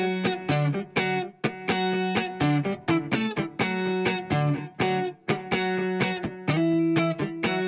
32 Guitar PT2.wav